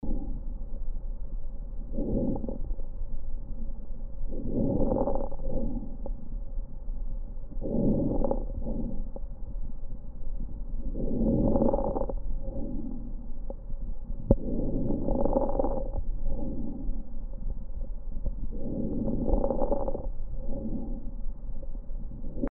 IPF患者の80％以上に肺底部の捻髪音（fine crackles）*と呼ばれる特徴的な音が聴取されます1)
*:「パチパチ」「バリバリ」という特徴的な音である捻髪音は、マジックテープをはがす音に似ていることから、マジックテープのメーカー(ベルクロ社)にちなみ、「ベルクロラ音」とも呼ばれます。